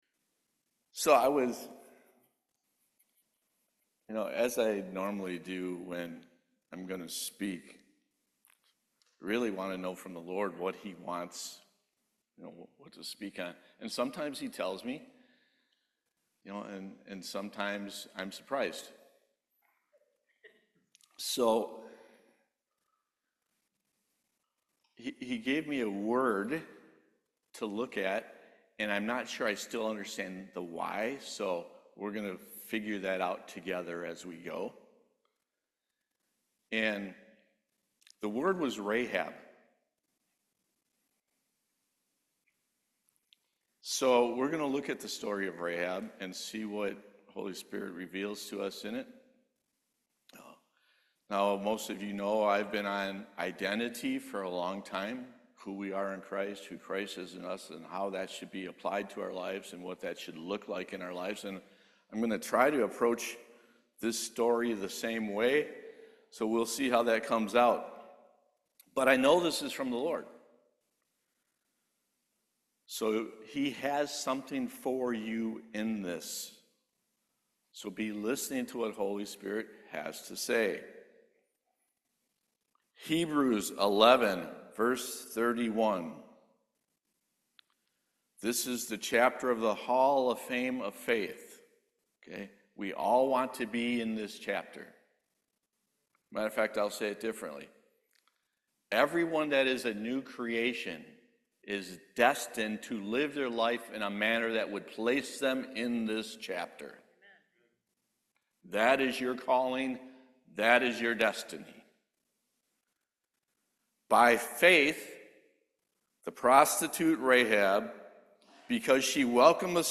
Matthew 1:5 Service Type: Main Service The only proper way for a new creation to live is from Heaven’s perspective.